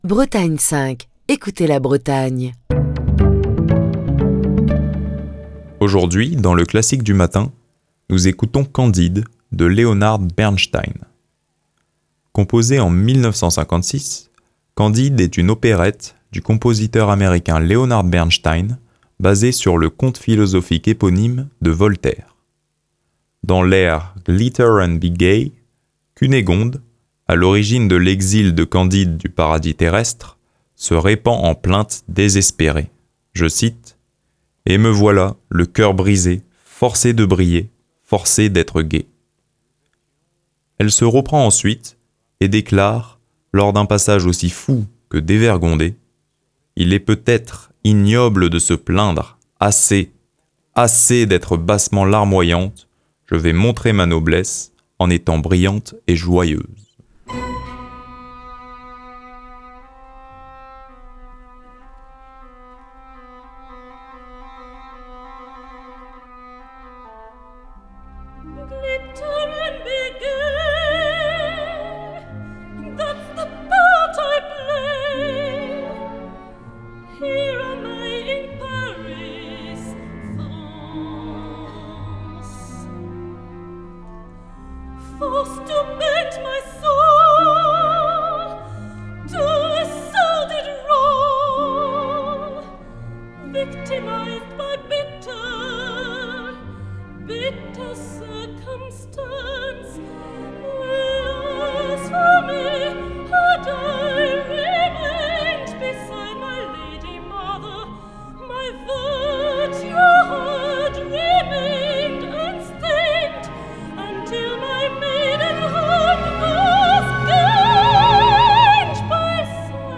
opérette
soprano colorature